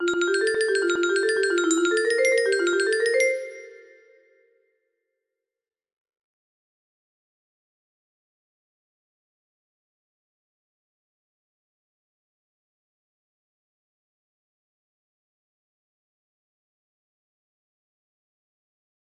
Gaudeamus Igitur music box melody